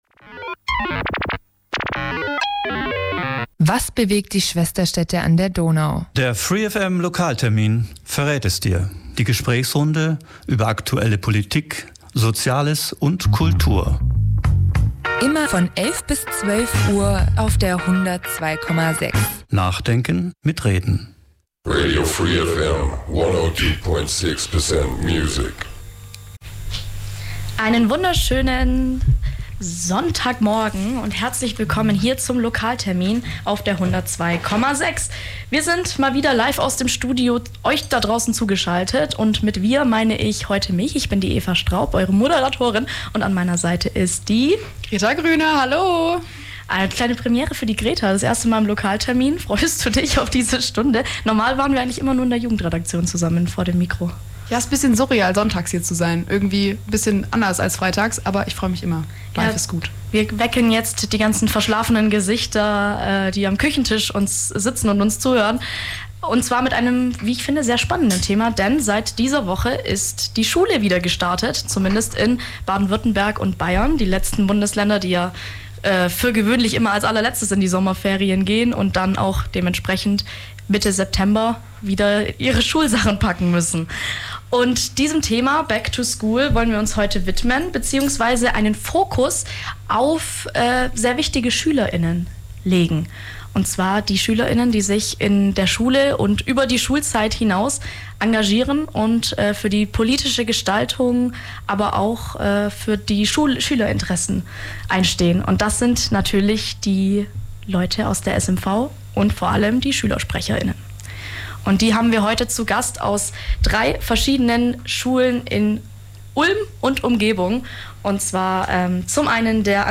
Der Radio free FM Lokaltermin verrät es Dir: die Gesprächsrunde über aktuelle Politik, Soziales und Kultur.